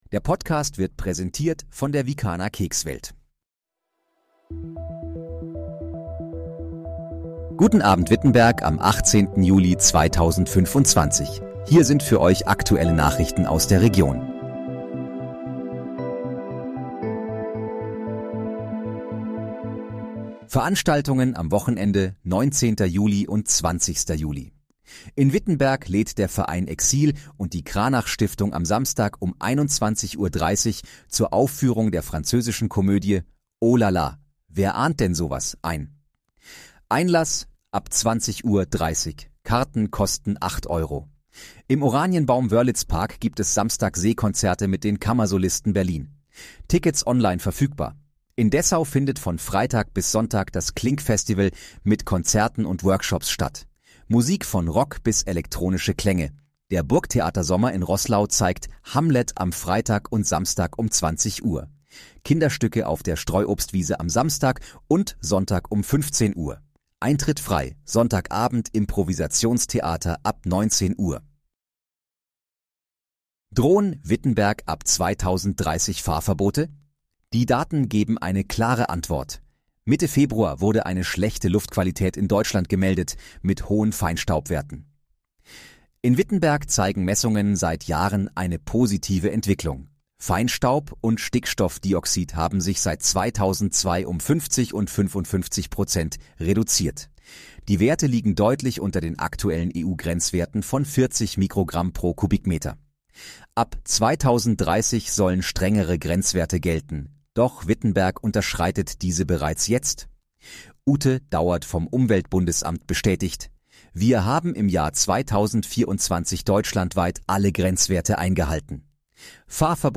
Guten Abend, Wittenberg: Aktuelle Nachrichten vom 18.07.2025, erstellt mit KI-Unterstützung
Nachrichten